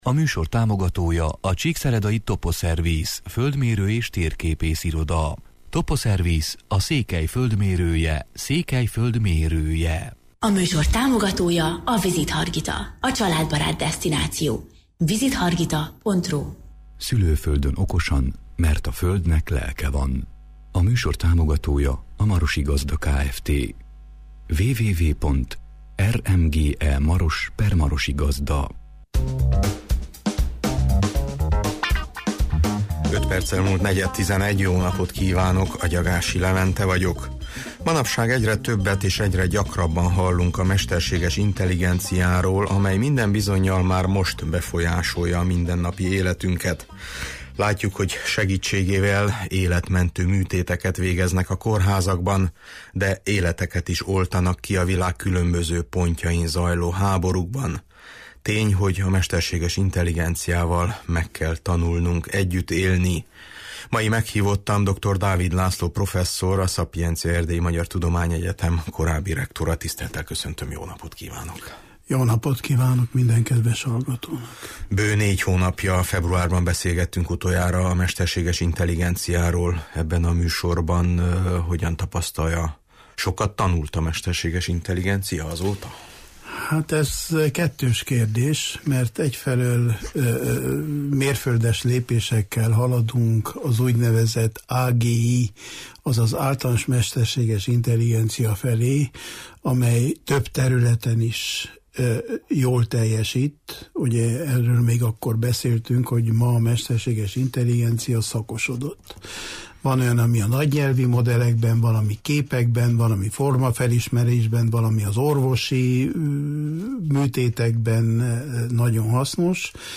A stúdióban